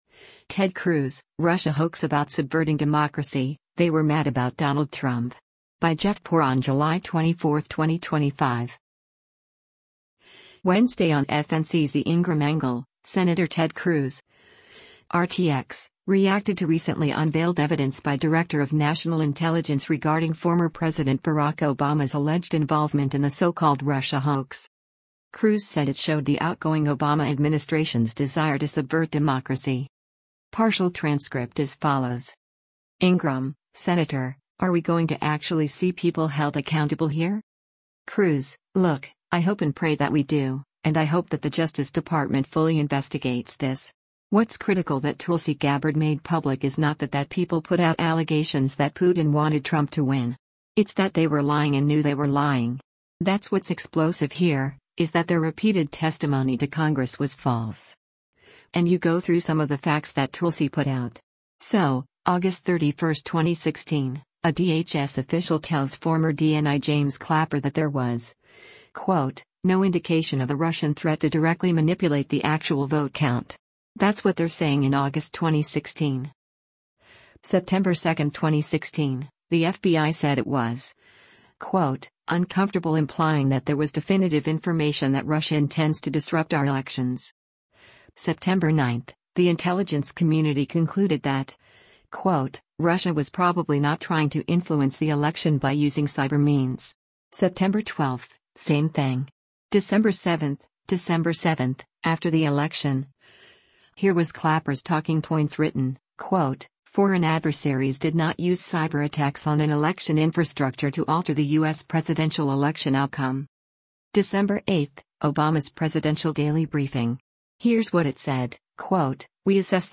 Wednesday on FNC’s “The Ingraham Angle,” Sen. Ted Cruz (R-TX) reacted to recently unveiled evidence by Director of National Intelligence regarding former President Barack Obama’s alleged involvement in the so-called Russia hoax.